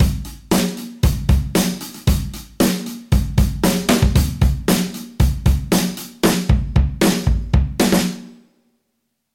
BFD 3.5 是 FXpansion 旗舰级原声鼓虚拟乐器，主打超真实多麦采样 + 深度物理建模 + 专业级混音控制，是影视、摇滚、金属、爵士等风格的顶级鼓制作工具BFD Drums。